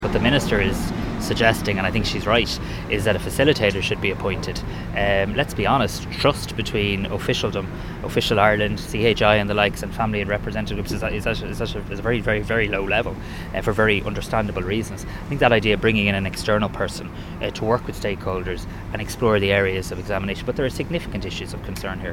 Speaking on his way into the meeting, Tanaiste Simon Harris said the facilitator would be a crucial part of the process.